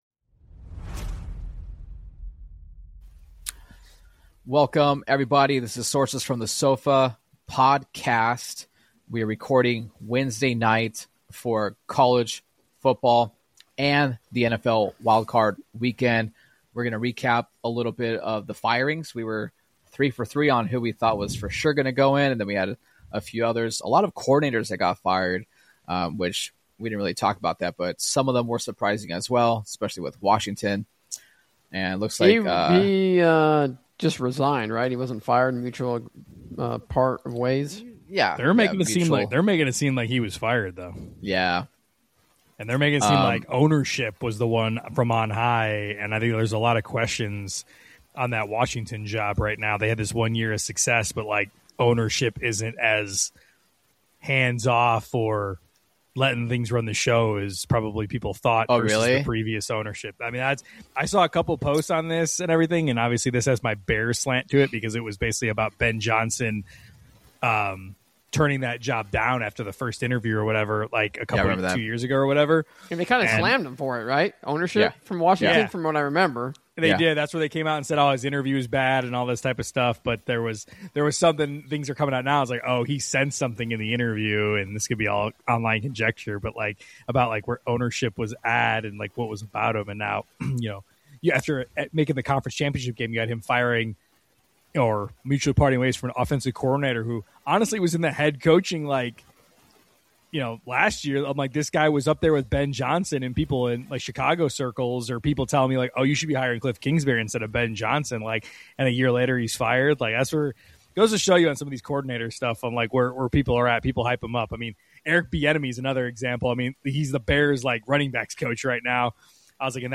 The guys give their picks and predictions for what is shaping up to be one of the better Wild-Card weekends in years for the NFL. If you caught the college football semi-final preview, first half may sound the same, because it is with a recap of Black Monday.